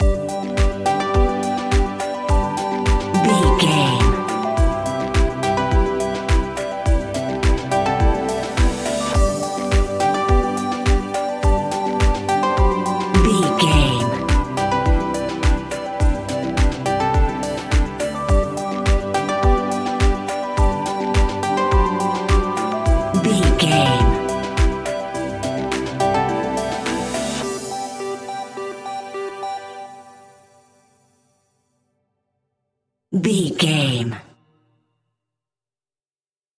Ionian/Major
groovy
uplifting
energetic
repetitive
synthesiser
drums
electric piano
strings
electronic
instrumentals